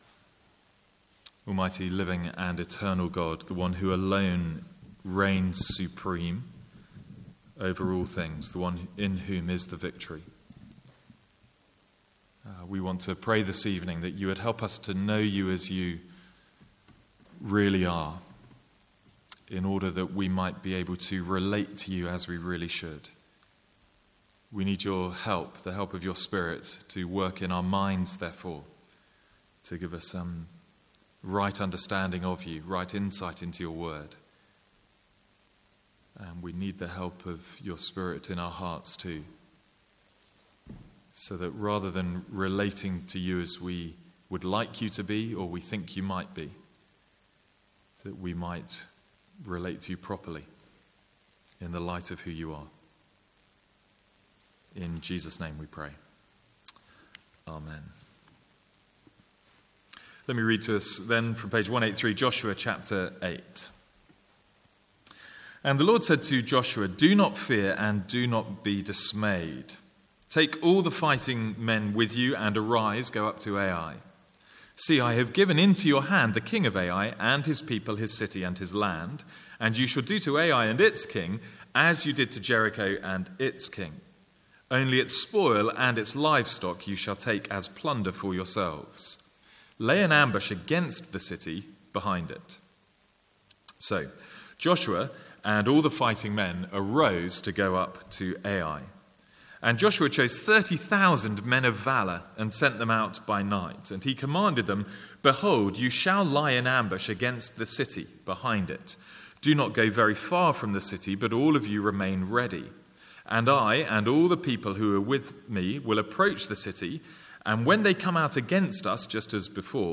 Sermons | St Andrews Free Church
From the Sunday evening series in Joshua.